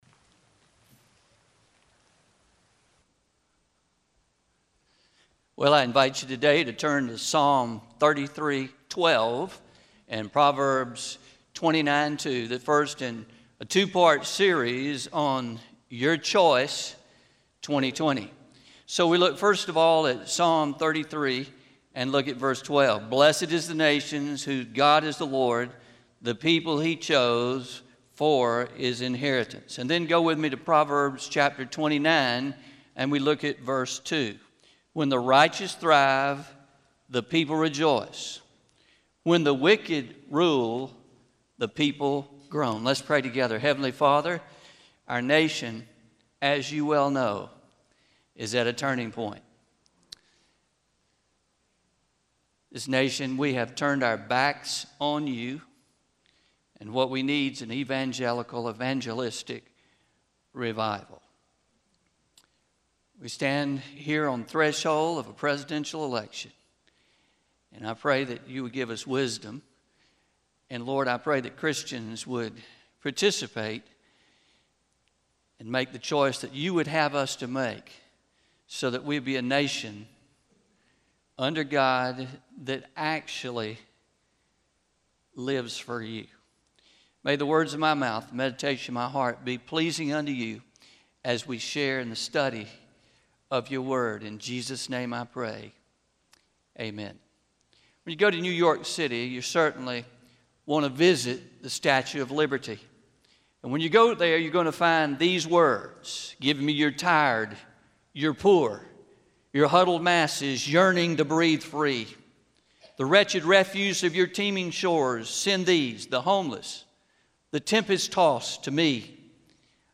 09-20-20am Sermon – Your Choice 2020 Part 1 – Traditional